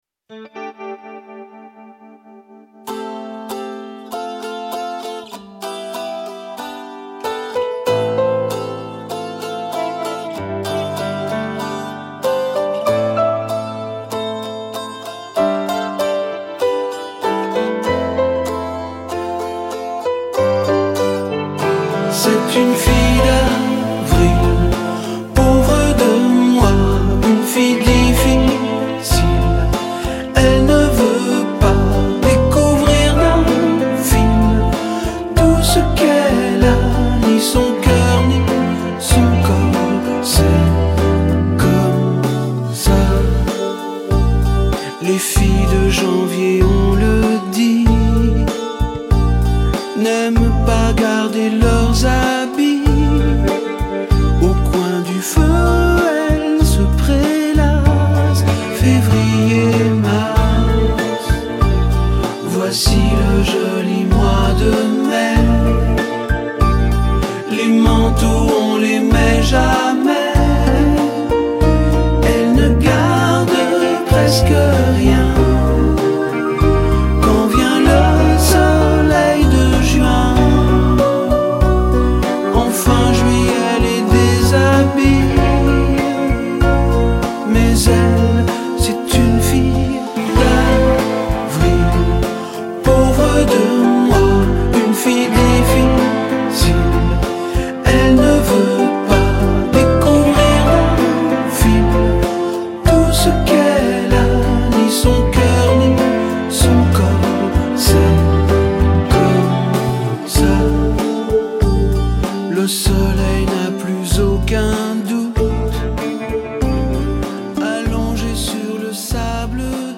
tonalités LA mineur et SIb majeur